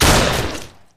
shotgun.mp3